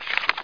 pageturn.mp3